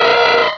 Cri de Dynavolt dans Pokémon Rubis et Saphir.